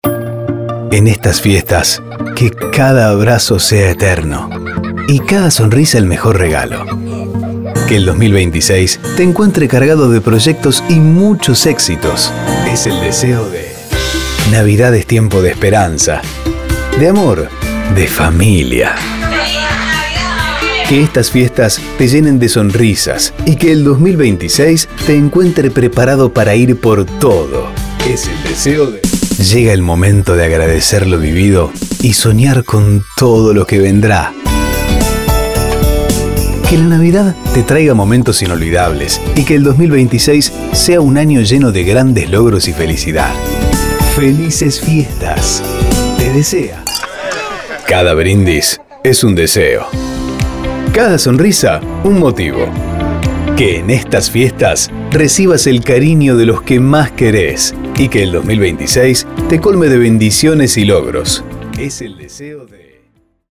Salutaciones premium para venderle a tus anunciantes o personalizarlas con los datos de tu radio.
✅ Fondos musicales y FX de máxima calidad.